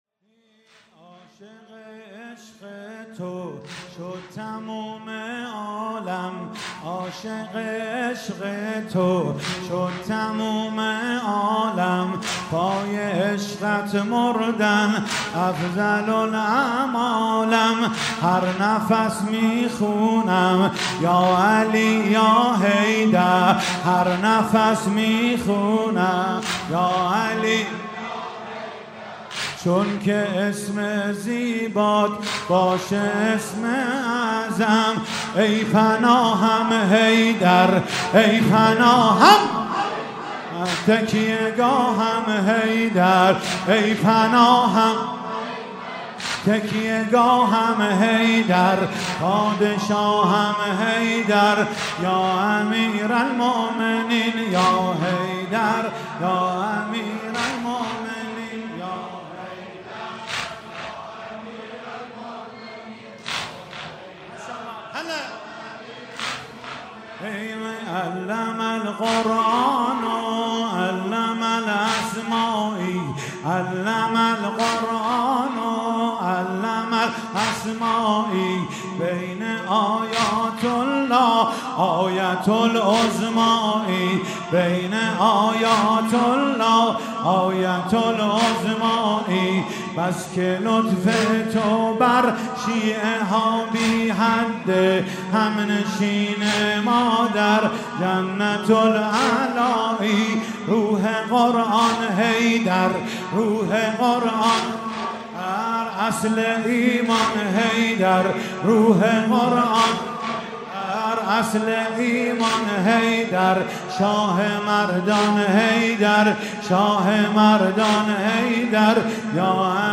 16 خرداد 97 - حسینیه انصار الحسین - واحد - عاشق عشق تو شد تموم عالم
شهادت امام علی (ع)